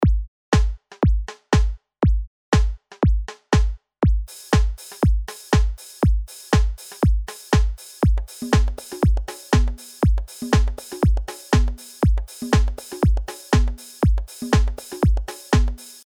Ohne eine bestimmte Drum-Machine explizit zu emulieren kommen für das Pop-, Dance- und Club-Genre alle auf ihre Kosten: Die Klangpalette subtraktiver Synthese und samplebasierte Drummachines wird quasi lückenlos abgebildet und durch die einzigartigen Funktionen, speziell durch die Vielzahl von Oszillator-Modellen erweitert.
Höre wir also mal rein: Hier habe ich drei Grooves übereinandergelegt.
Der Klang ist sauber, markant und frisch. Die Audioqualität spielt sich auf zeitgemäß hohem Niveau ab.